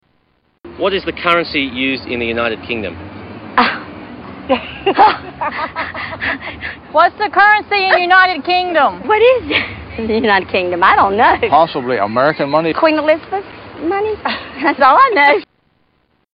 Tags: Media Stupid Americans Chasers Funny UK T.V. Show